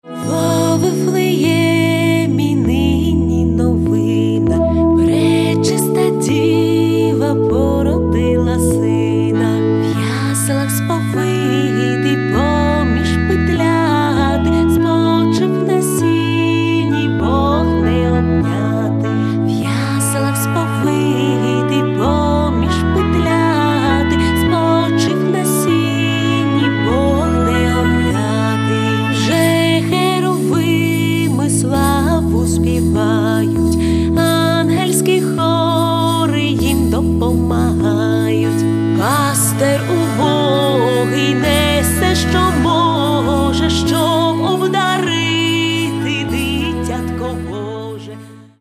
тож настрій по собі альбом залишає світлий.